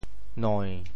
“楝”字用潮州话怎么说？
楝 部首拼音 部首 木 总笔划 13 部外笔划 9 普通话 liàn 潮州发音 潮州 noin7 白 liêng7 文 中文解释 楝〈名〉 楝树 [chinaberry]。